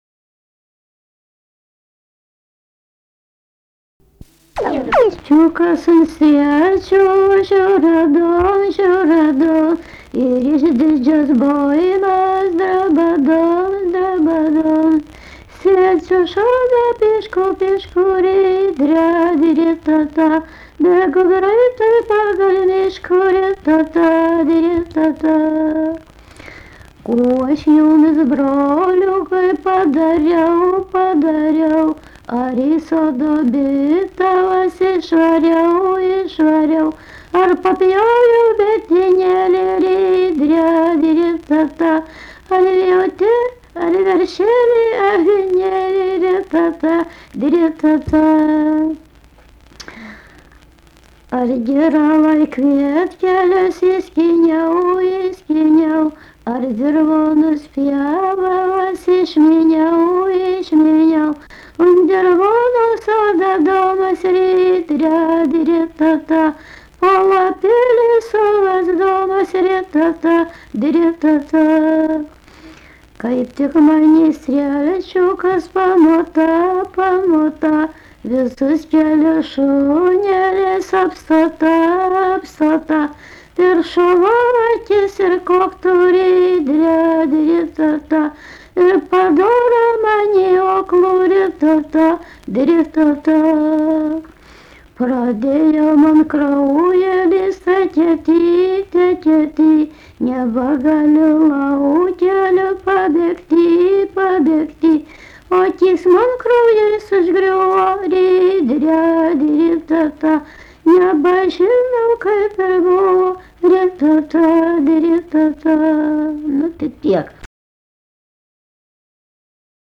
daina
Rėkučiai
vokalinis